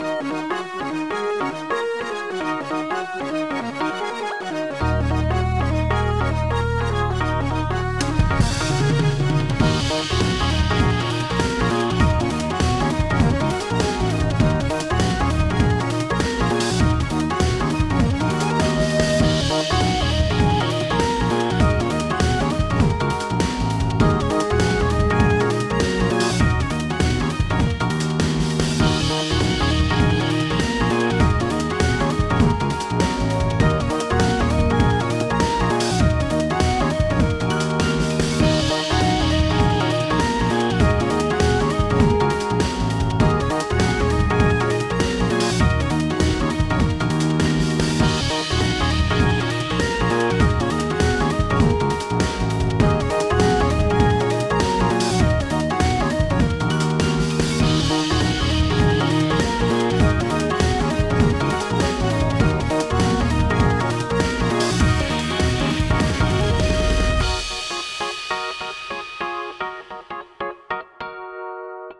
80s JRPG – Soundtracks
This composition captures the essence of classic 80s JRPG soundtracks, featuring nostalgic synth melodies, rich harmonies, and expressive chiptune elements. Inspired by the golden era of role-playing games, the piece evokes a sense of adventure and emotion, bringing retro game worlds to life with an authentic vintage sound.